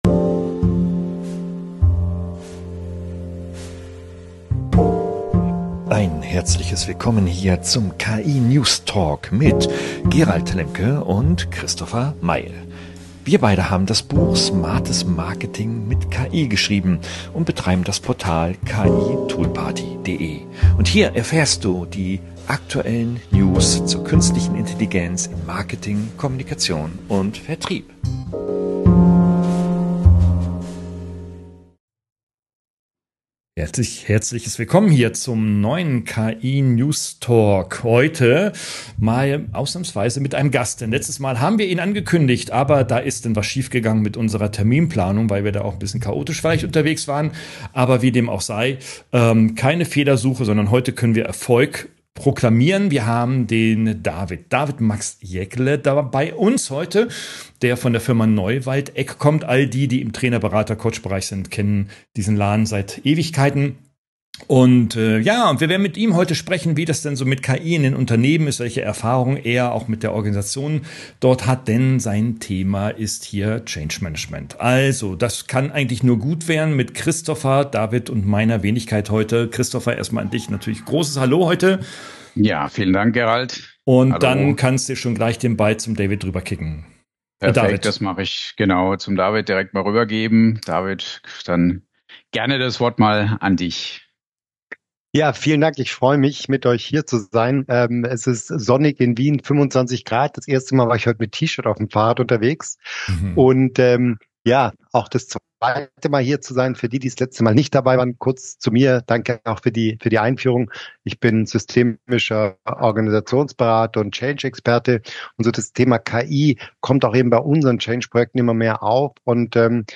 KI News TALK 35 - KI im Unternehmen - Streitgespräch ~ Jeder Kann KI